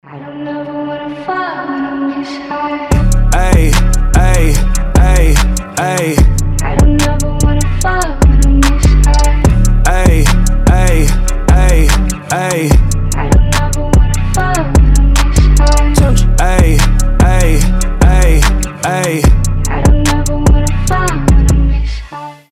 • Качество: 320, Stereo
гитара
Хип-хоп
красивый женский голос
Alternative Hip-hop